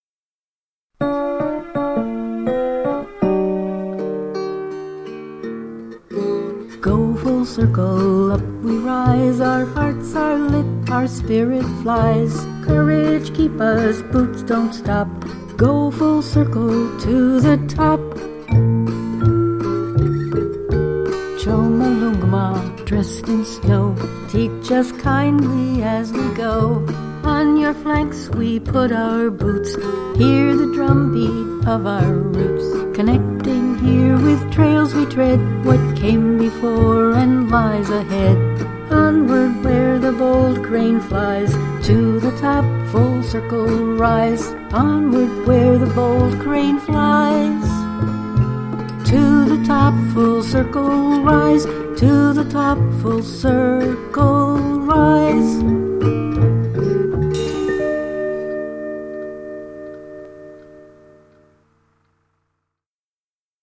What you see here is a rough and quick production using minimal equipment, in the interest of getting it to the team before they leave Base Camp for the big UP.